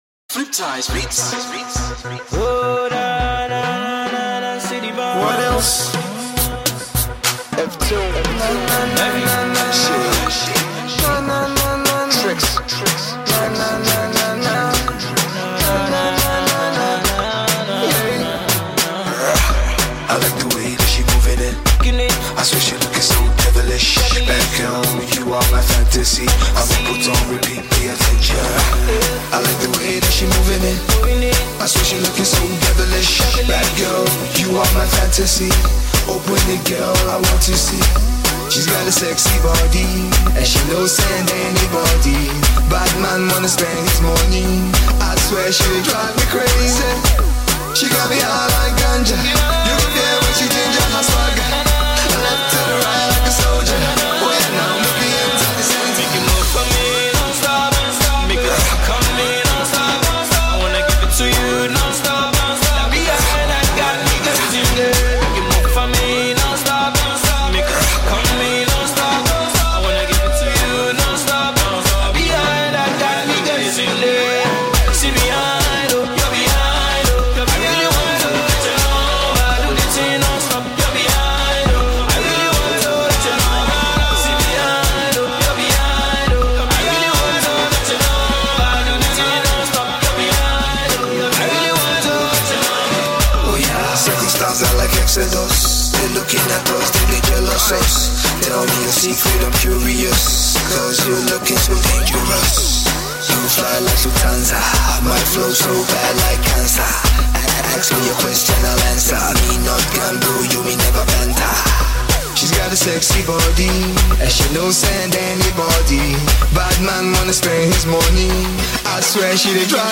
Pop/Rap
the smooth mid-tempo dance musical backdrop
captivating rap/vocal flow